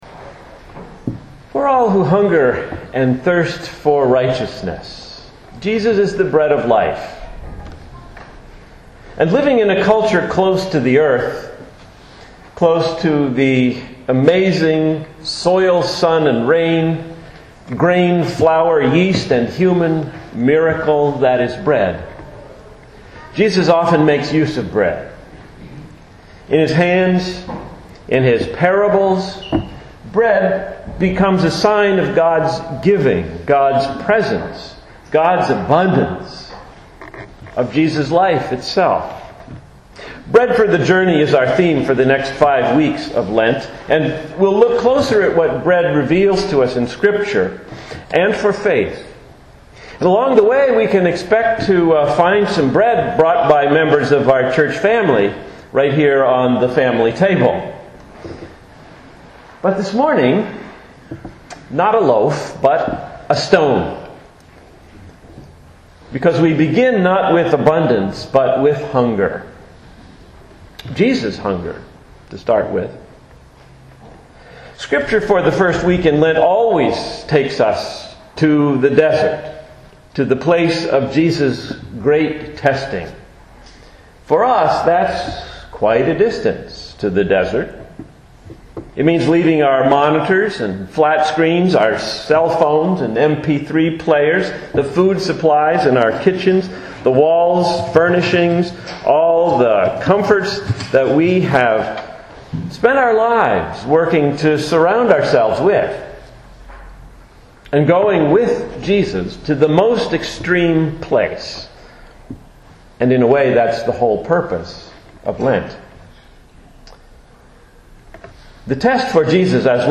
The second, for the first Sunday in Lent, a sermon on the bread of life.